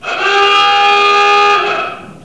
alarm02.wav